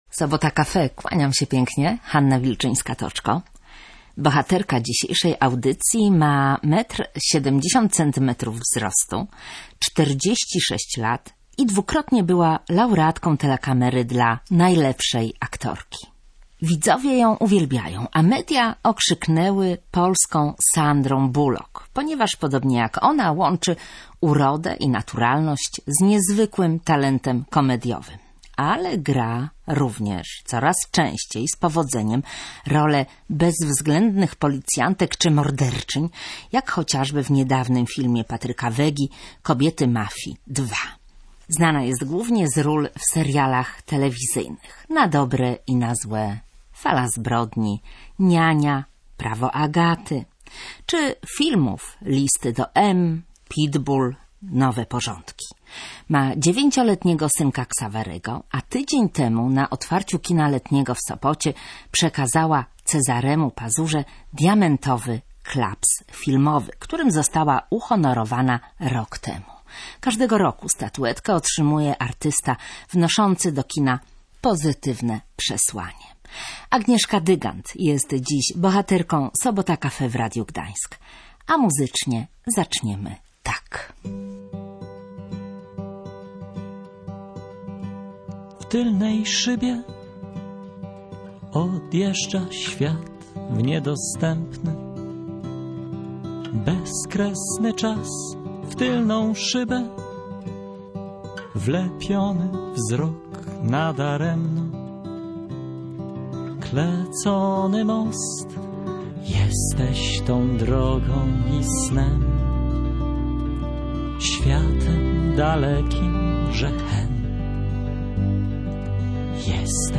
Na planie filmowym czuje się jak ryba w wodzie, za to nie przepada za teatrem. Agnieszka Dygant w Radiu Gdańsk